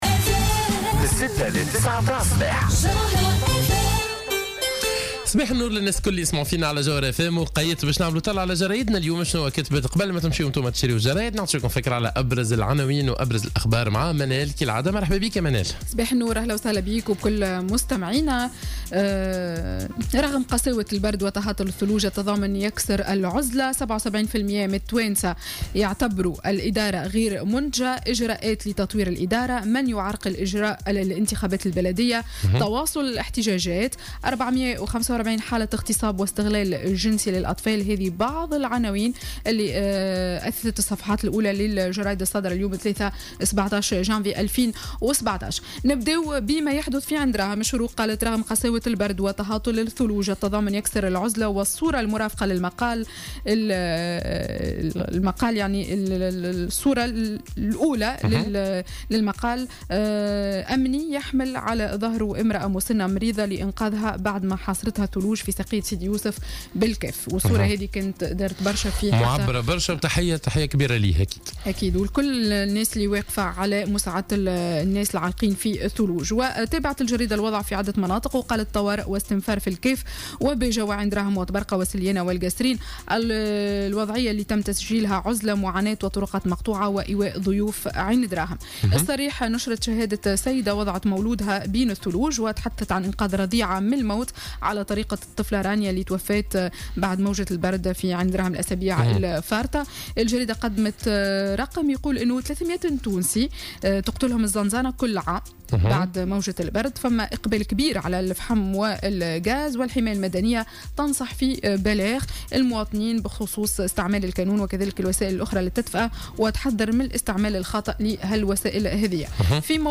Revue de presse du mardi 17 janvier 2017